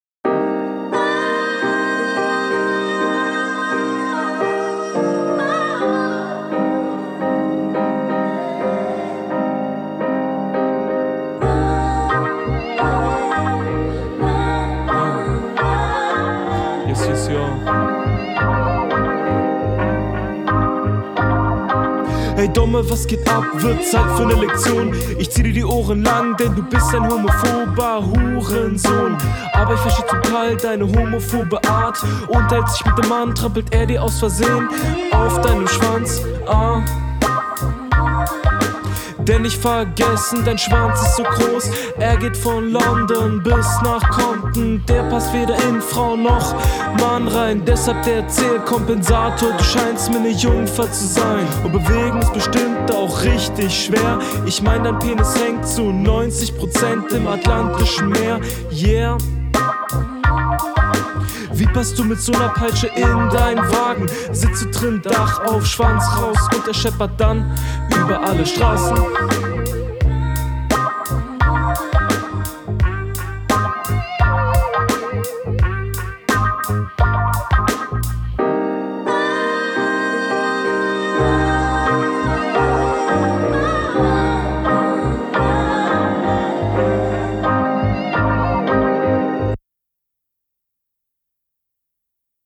Sound und Flow: Beatwahl wieder cool von dir.
Mix wieder ganz gut.